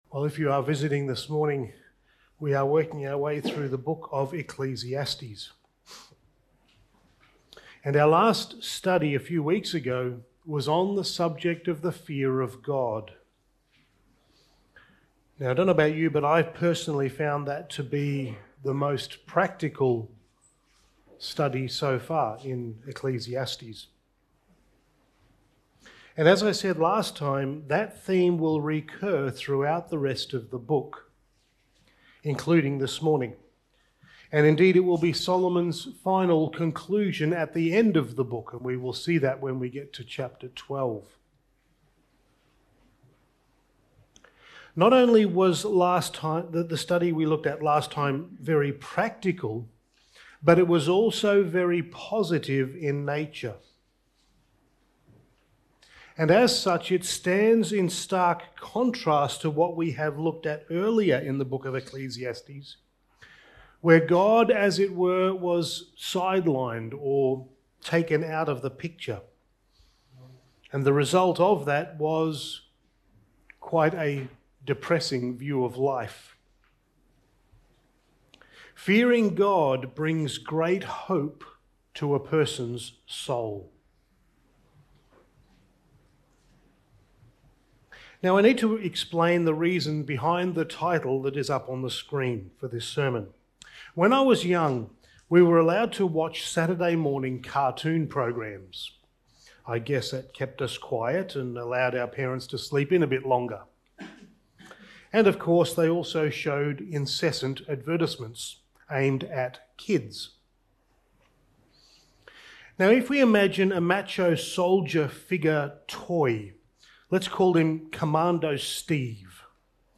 Passage: Ecclesiastes 5:8-20 Service Type: Sunday Morning